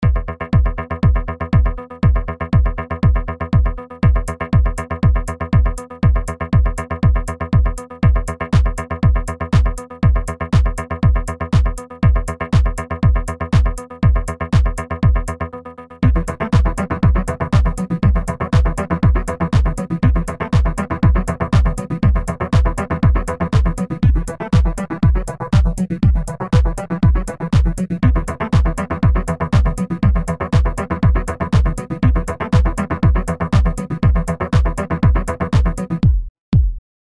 A short title screen loop with some stylish synth.